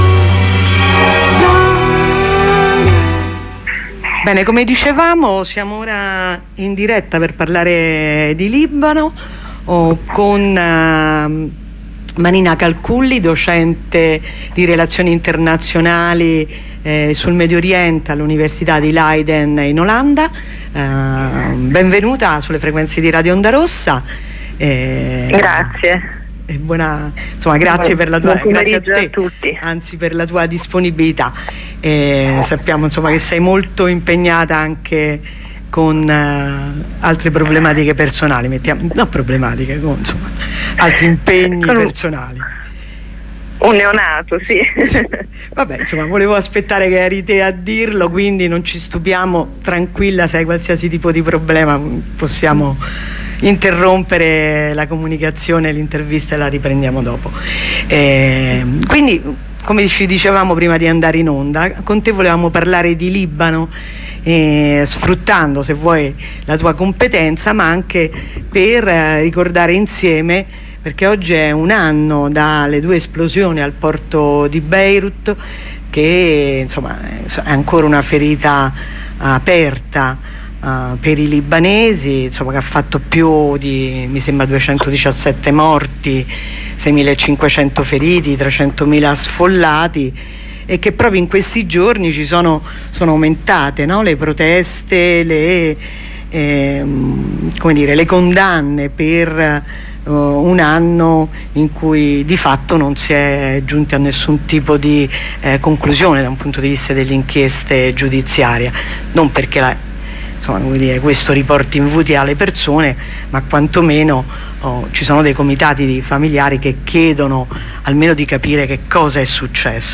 h 15:20 collegamento dalla nuova occupazione di bologna